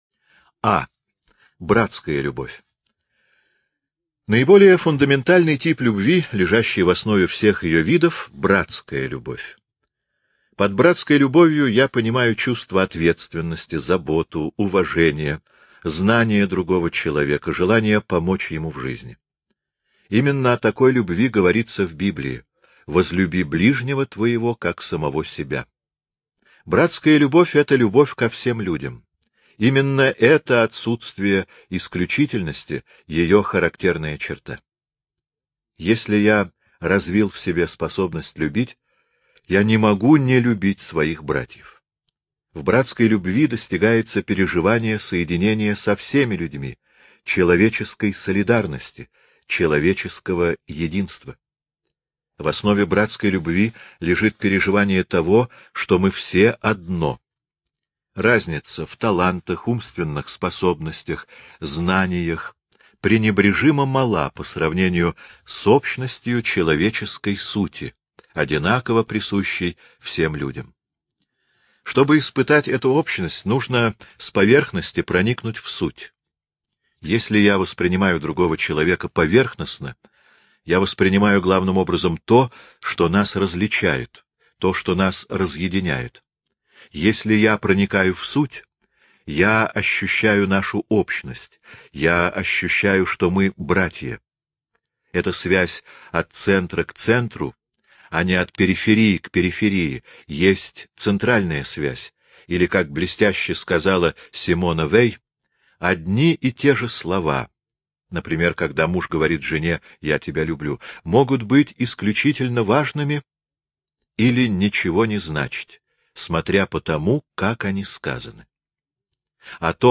Жанр: Speech.